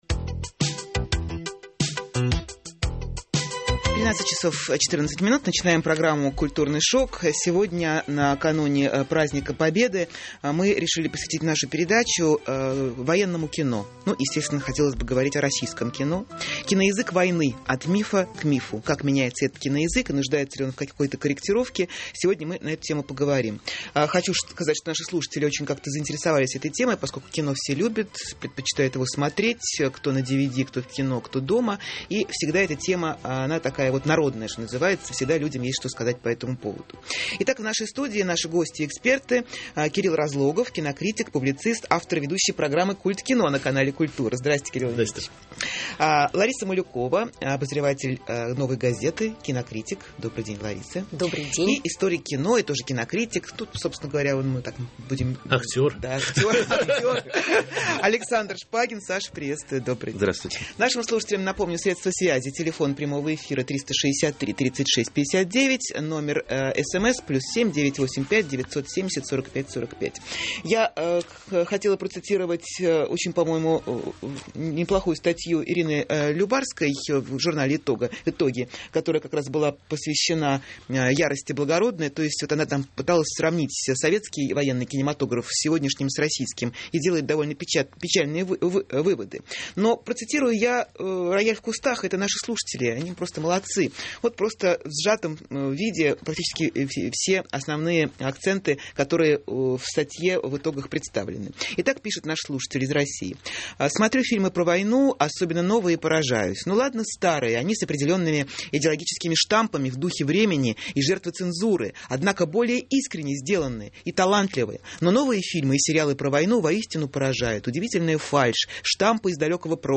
Итак, в нашей студии наши гости и эксперты: Кирилл Разлогов, кинокритик, публицист.